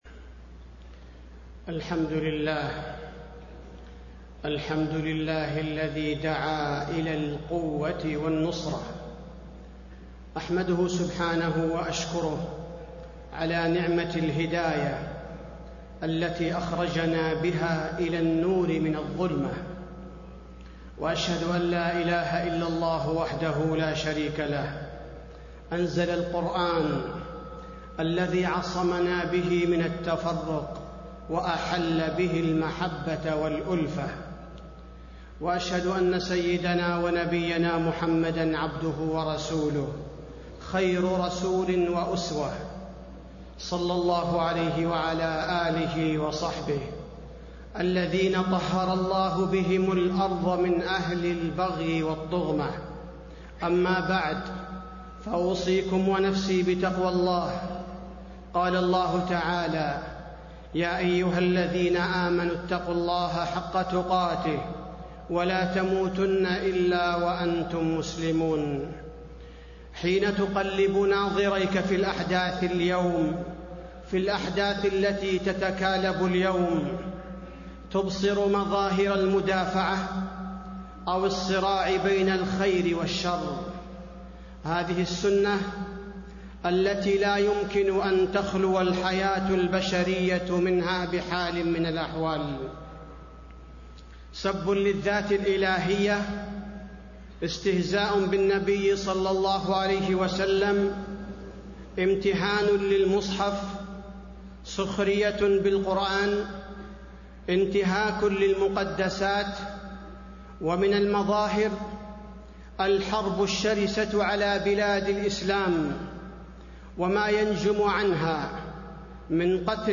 تاريخ النشر ١٩ ربيع الثاني ١٤٣٤ هـ المكان: المسجد النبوي الشيخ: فضيلة الشيخ عبدالباري الثبيتي فضيلة الشيخ عبدالباري الثبيتي وسائل نصرة دين الله